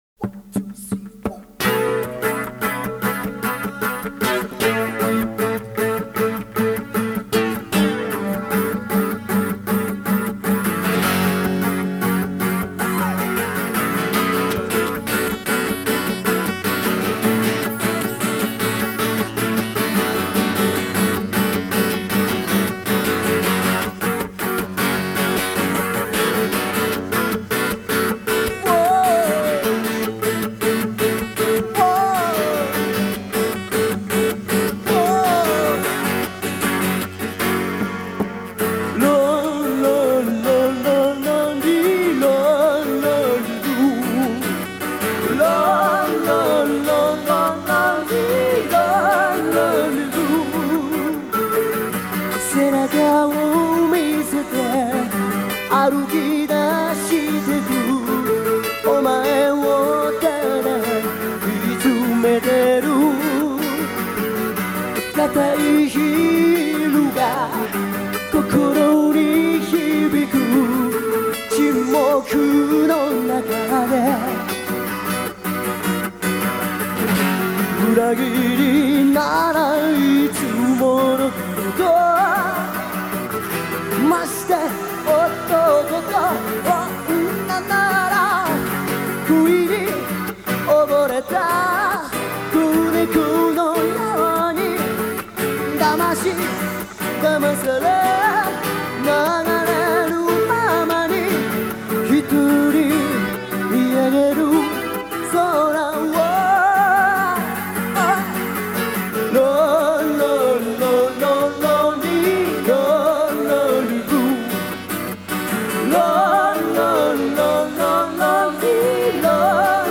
1985年9月22日に行われた記念コンサート
MORE「Lonely Blue」